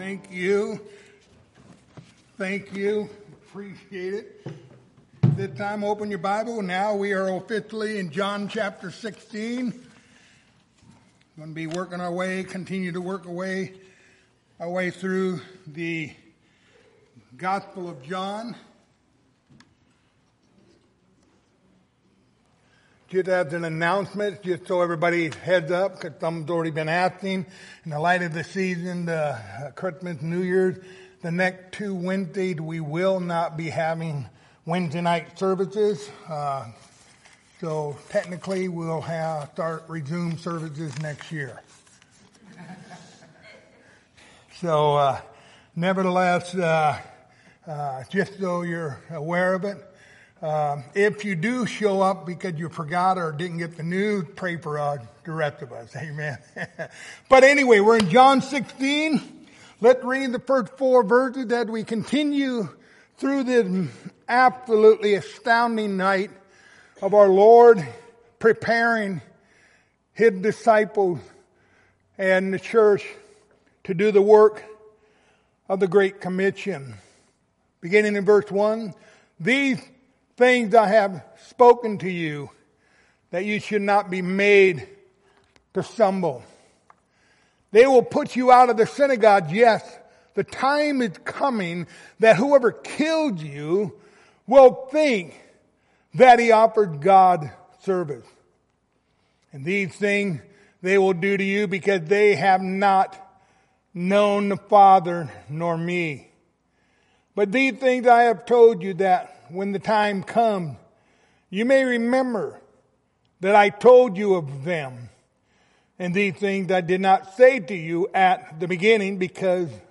John 16:1-4 Service Type: Wednesday Evening Topics